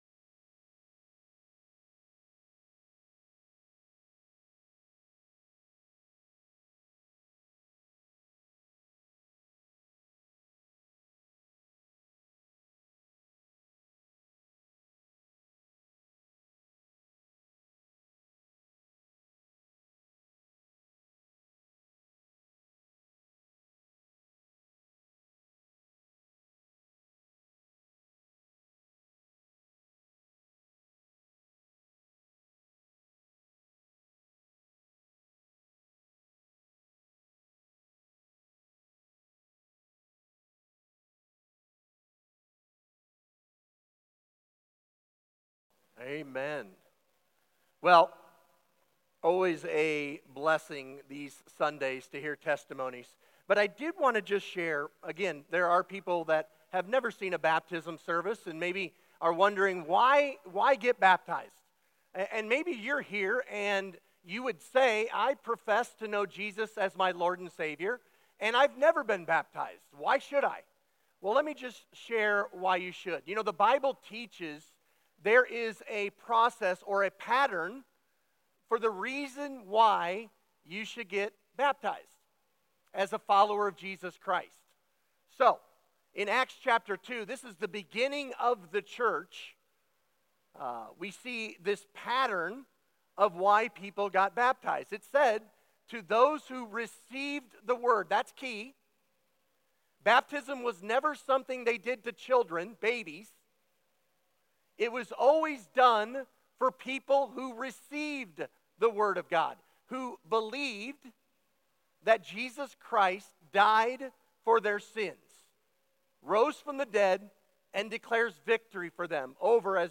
August 24, 2025 Baptism Sunday | High Pointe Church
Baptism Testimonies